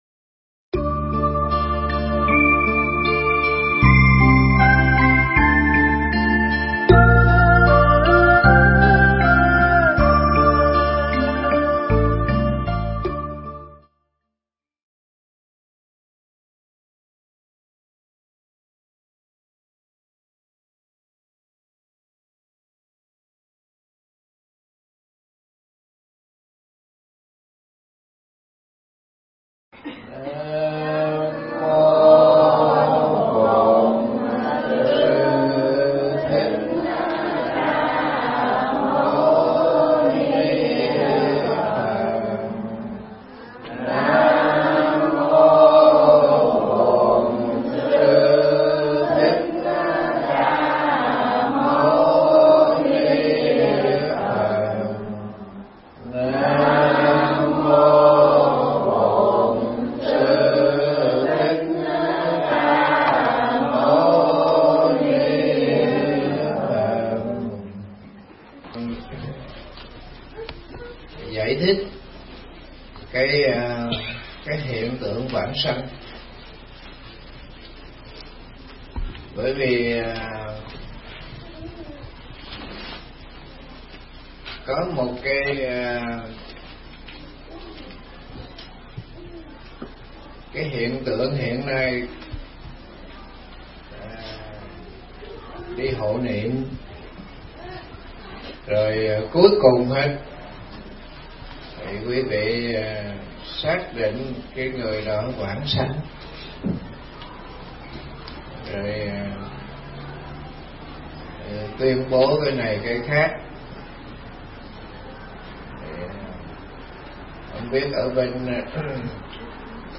Nghe Mp3 thuyết pháp Hiện Tướng Vãng Sanh